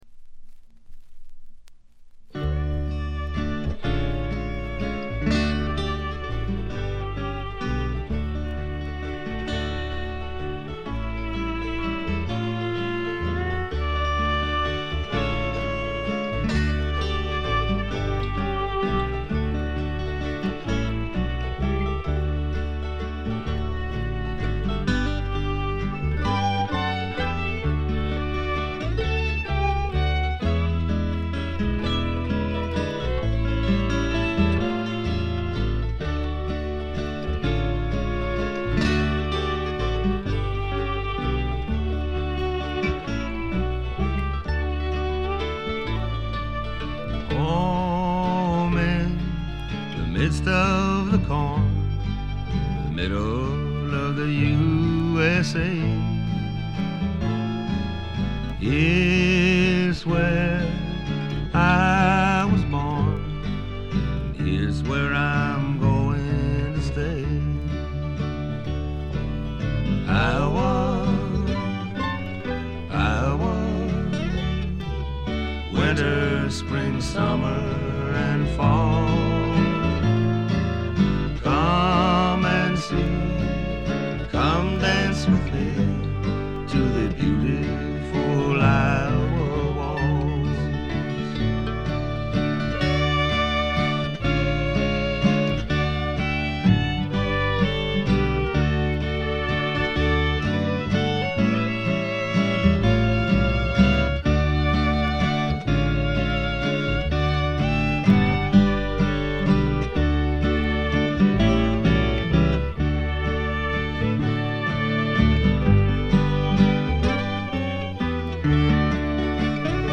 にがみばしった男の哀愁を漂わせたヴォーカルがまず二重丸。
試聴曲は現品からの取り込み音源です。
Guitar, Vocals
Bass
Drums
Fiddle
Harmonica
Mandolin, Guitar, Banjo